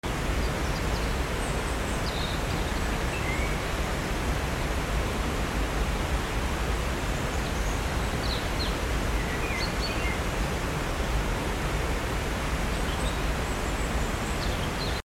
Gliding slowly over a series of cascades in a beautifully lush creek in the forest. The sound of the water, the vibrant greens, and the filtered light through the trees bring such a sense of peace.